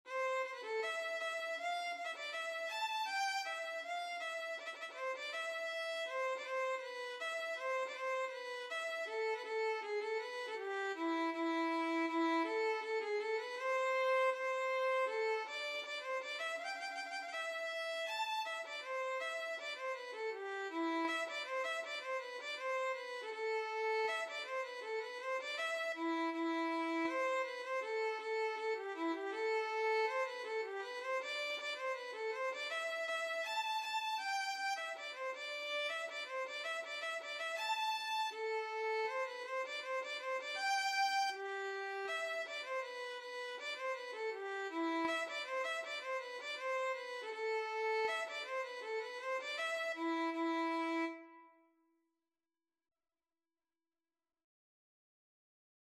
Violin version
2/2 (View more 2/2 Music)
Violin  (View more Easy Violin Music)
Traditional (View more Traditional Violin Music)